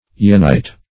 Search Result for " yenite" : The Collaborative International Dictionary of English v.0.48: Yenite \Ye"nite\, n. [After Jena, in Germany.]